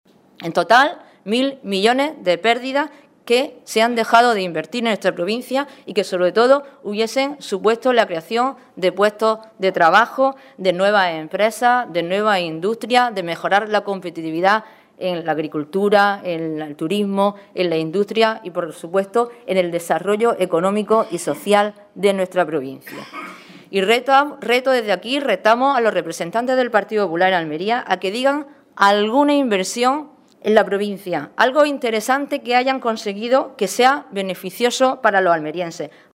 Rueda de prensa que ha ofrecido el Grupo Parlamentario nacional del PSOE de Almería